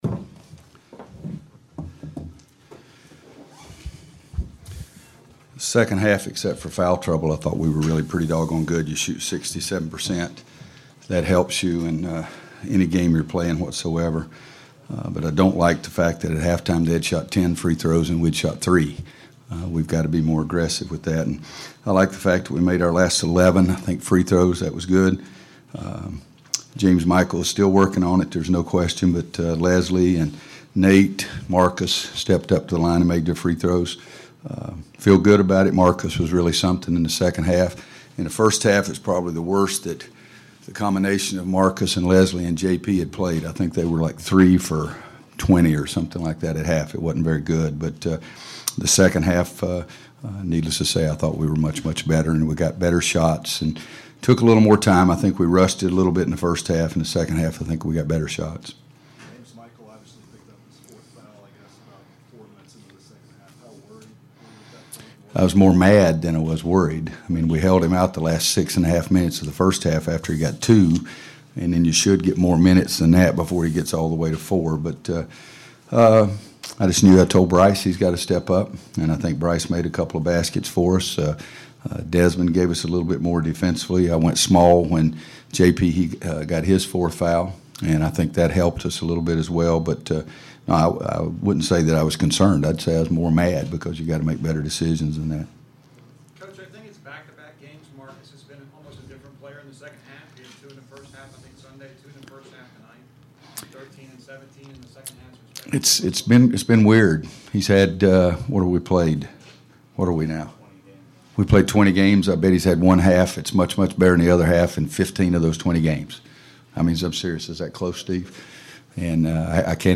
Coach Williams postgame comments following win over GT